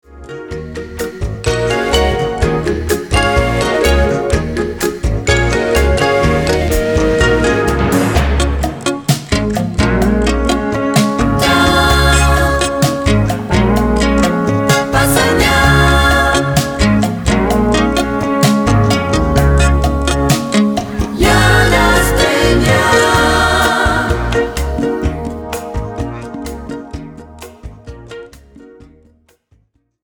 electroacoustic pedal harp, gu-cheng & more...
Recorded and mixed at the Sinus Studios, Bern, Switzerland